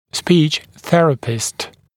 [spiːʧ ‘θerəpɪst][спи:ч ‘сэрэпист]логопед